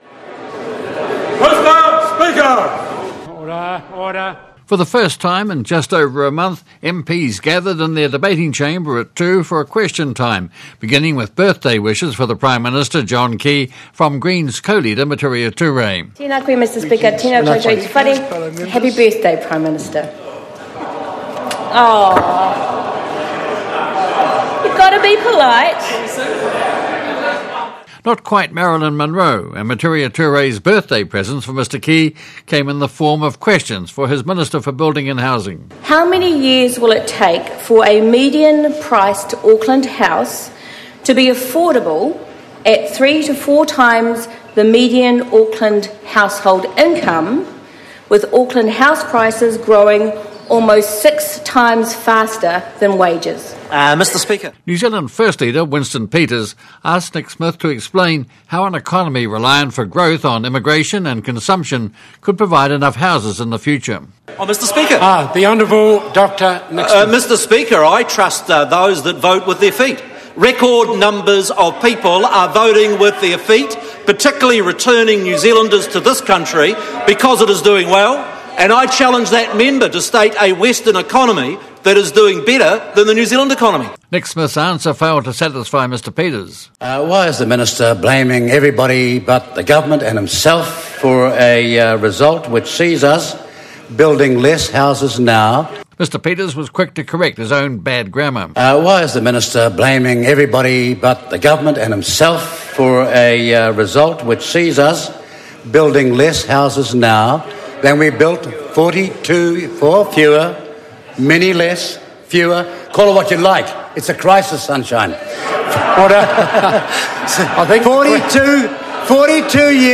Birthday wishes for the prime minister from Mitiria Turei when MPs gather in their debating chamber for their first Question Time in just over a month. Questions dominated by housing issues of prices and supply.